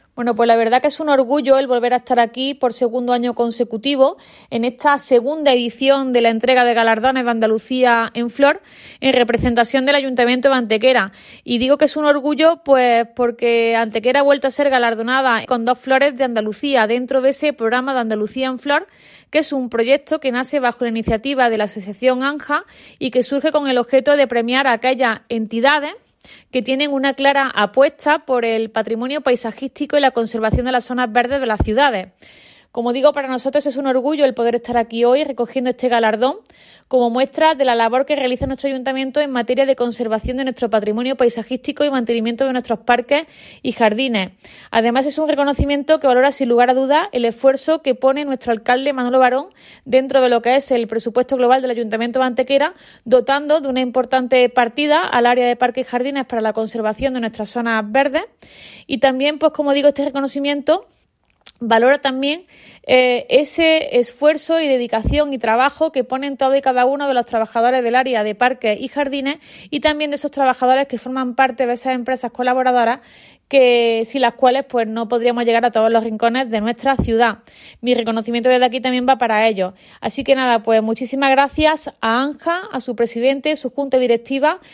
La teniente de alcalde Teresa Molina manifiesta el orgullo que supone el hecho de que Antequera haya recibido este galardón por segundo año consecutivo, “muestra de la labor que desarrolla nuestro Ayuntamiento en materia de conservación y mantenimeinto de patrimonio paisajístico, parques y jardines”, ensalzando la sensibilidad del alcalde Manolo Barón a la hora de dotar de un gran presupuesto a esta labor municipal.
Cortes de voz